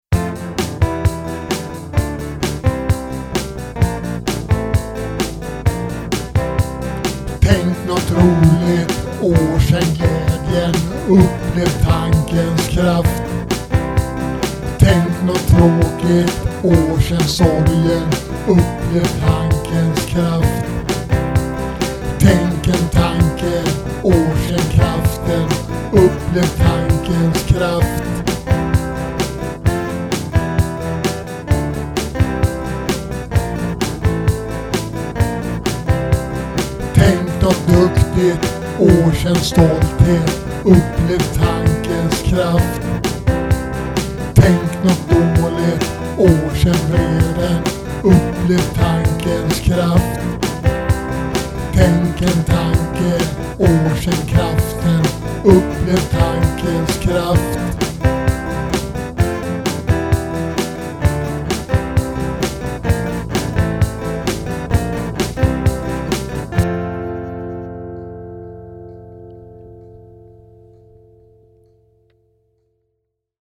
Capo på 2:a band = original tonart G
3/4 tempo